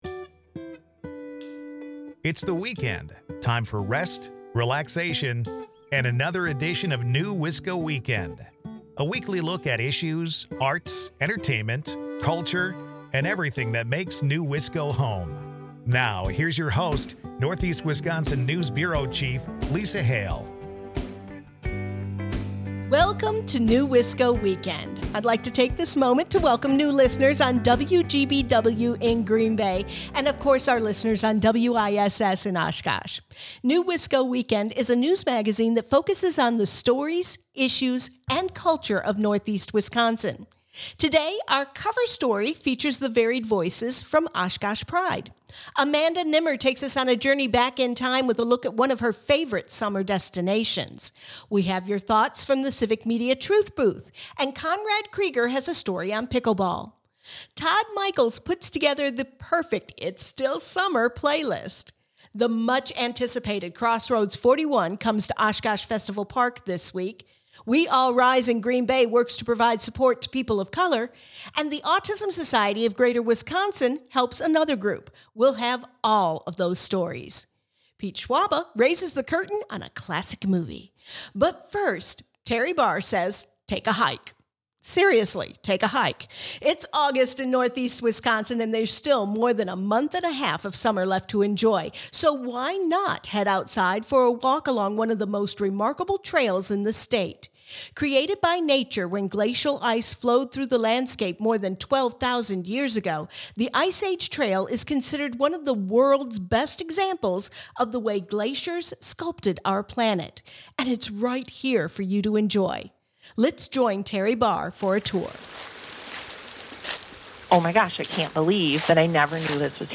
And in our cover story, we’ll hear the varied voices from Oshkosh Pride.